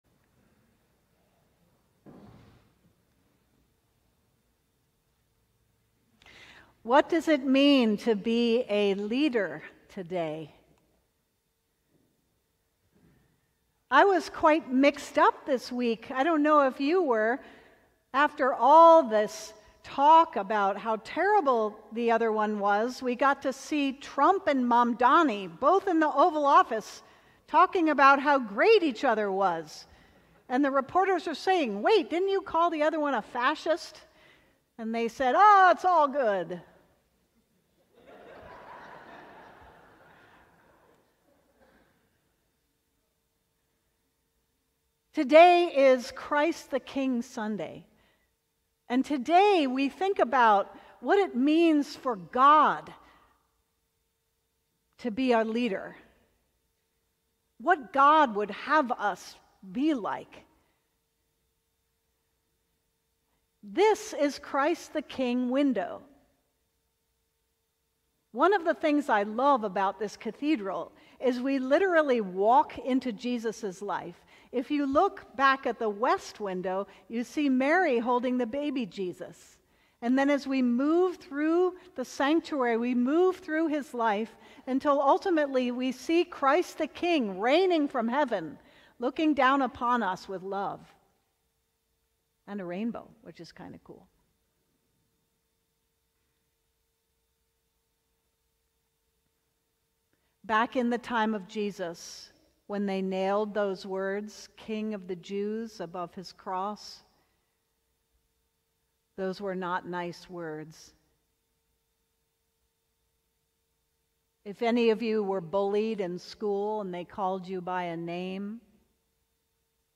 Sermon: Trusting the King